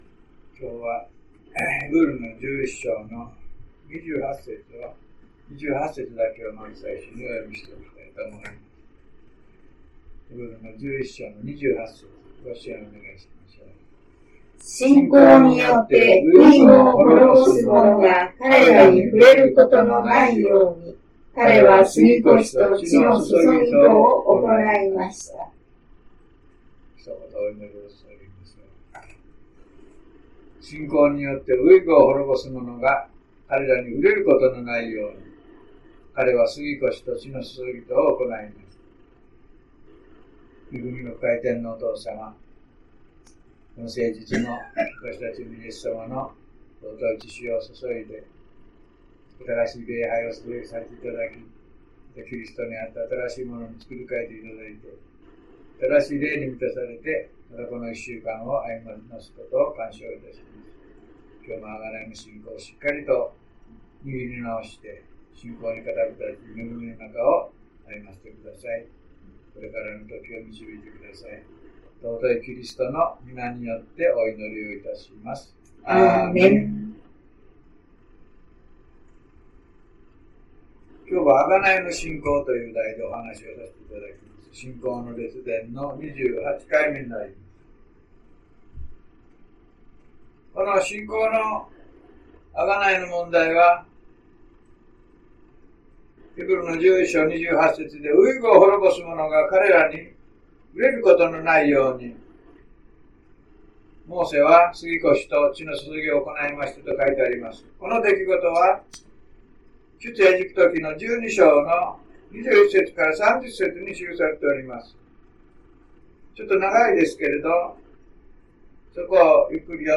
2017年2月19日 (日) 午前10時半 礼拝メッセージ